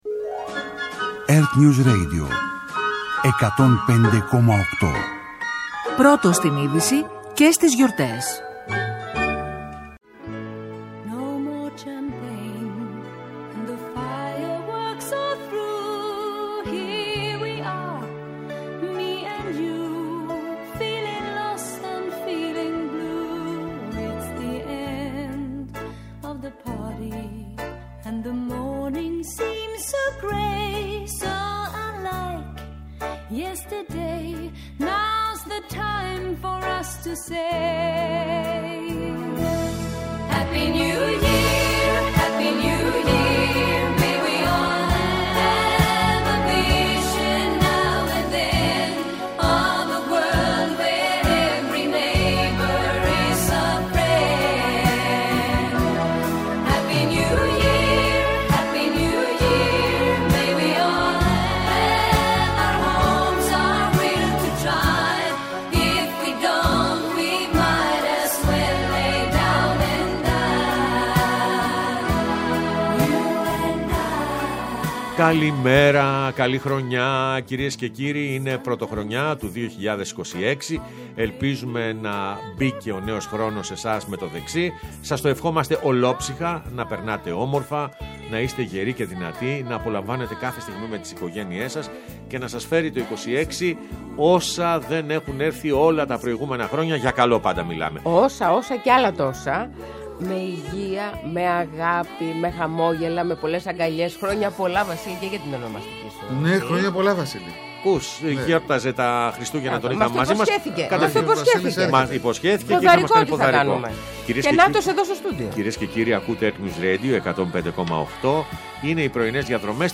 Ο Χρήστος Χωμενίδης έρχεται στο στούντιο του ΕΡΤnews Radio 105,8